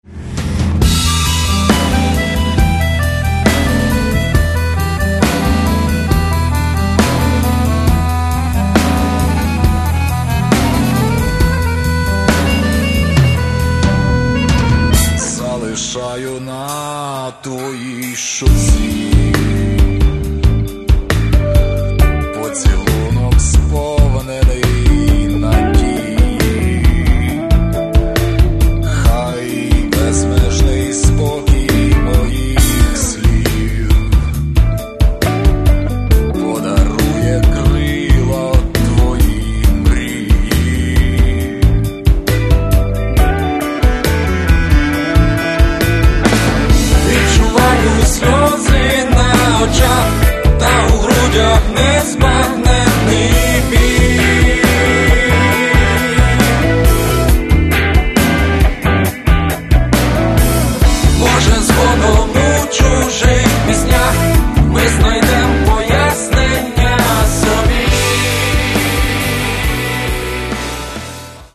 Каталог -> Рок и альтернатива -> Фольк рок